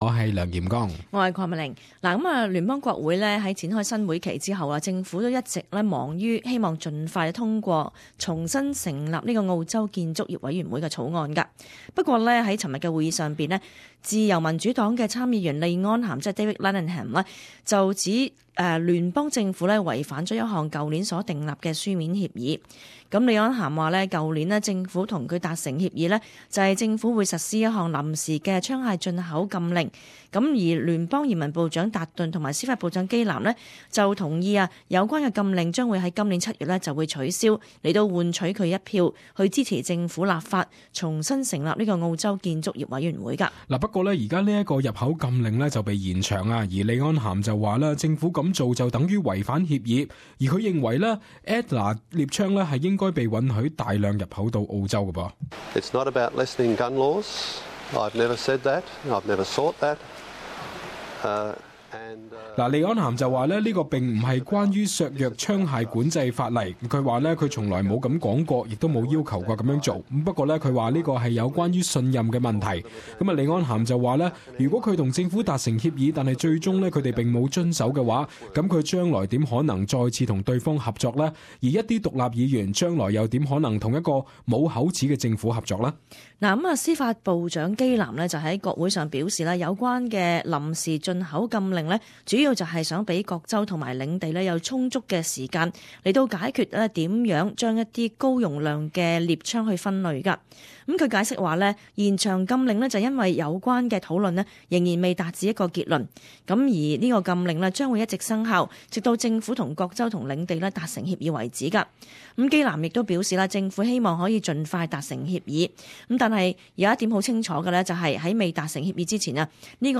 [時事報導] 政府被指違反解除管制槍械入口協議